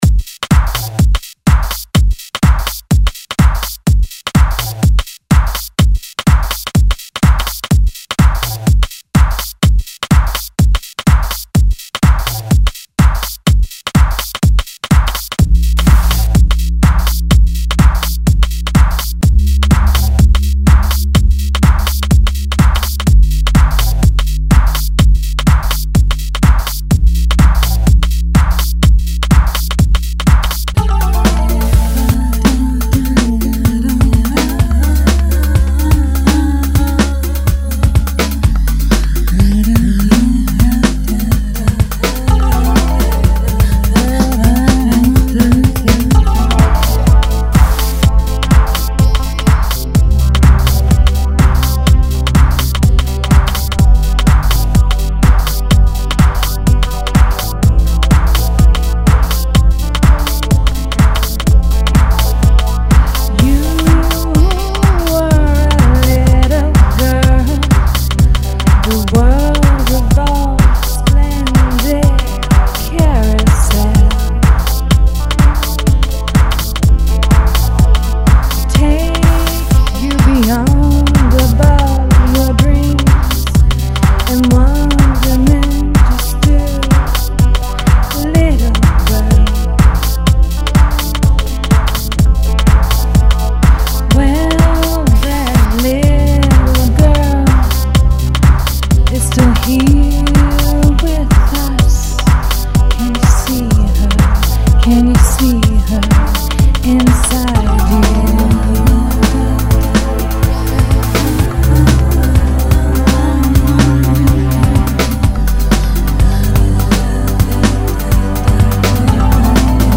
dance/electronic
Vocal driven house tune.
House
Techno
Breaks & beats